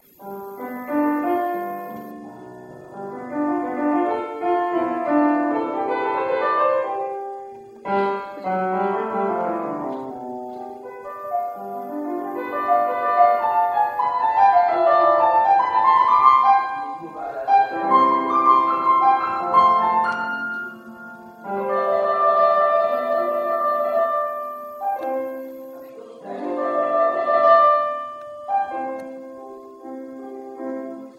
[17]高潮的地方稍微给一个rubato，tr的时候稍微等一等再回到主和弦：